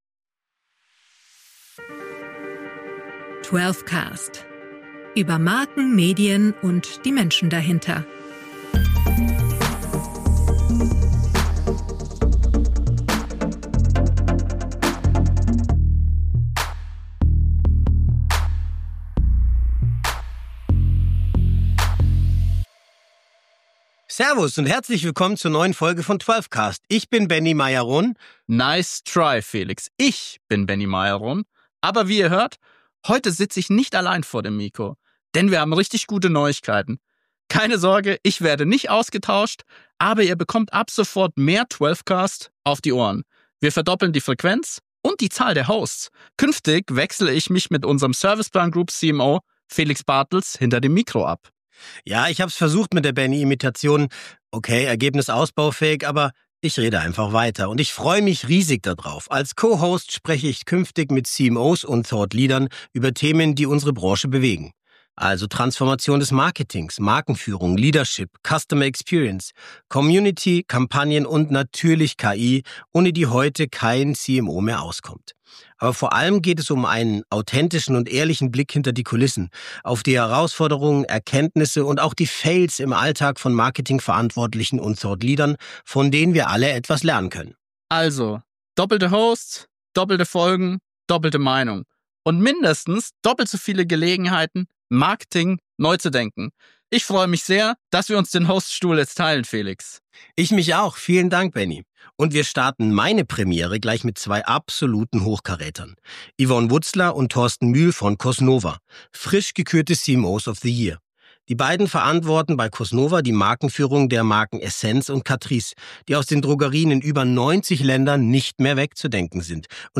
Ein offenes Gespräch über Führung, Wettbewerb, KI – und Vorsätze.